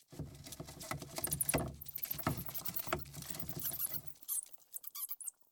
sfx_老鼠声.ogg